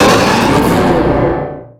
Cri de Méga-Élecsprint dans Pokémon X et Y.
Cri_0310_Méga_XY.ogg